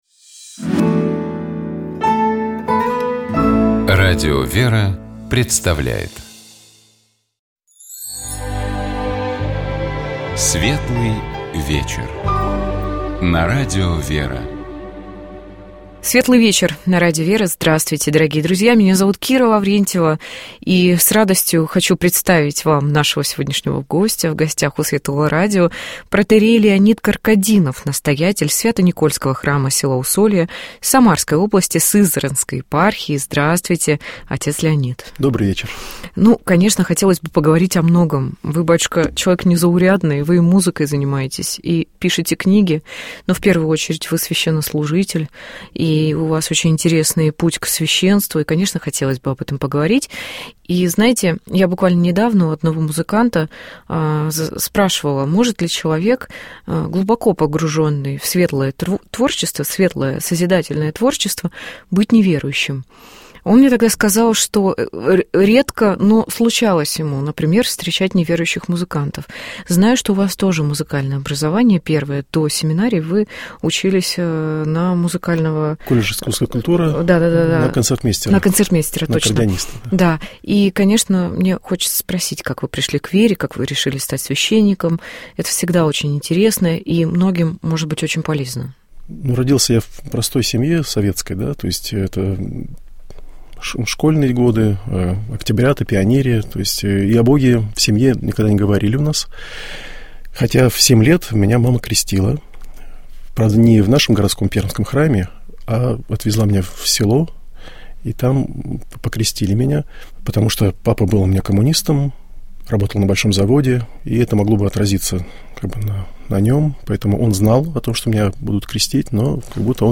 У нас в гостях был